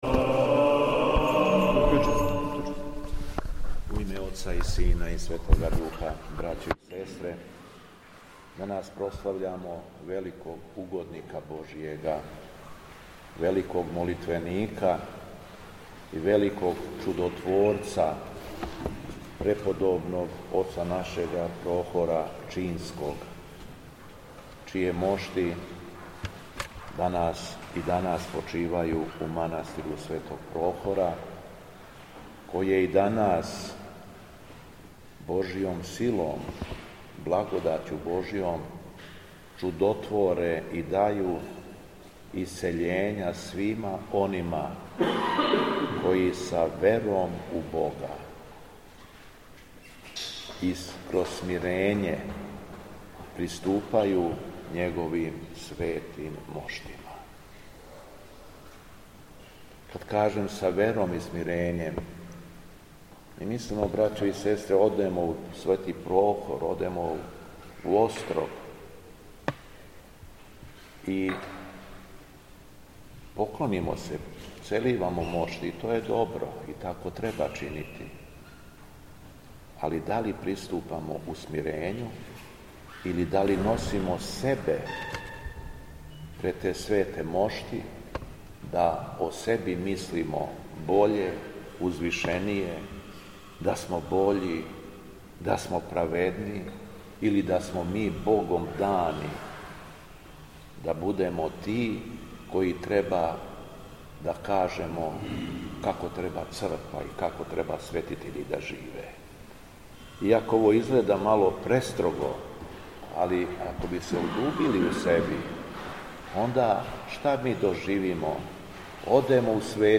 Беседа Његовог Високопреосвештенства Митрополита шумадијског г. Јована
После прочитаног јеванђелског зачала, Високопреосвећени Митрополит се обратио беседом сабраном народу: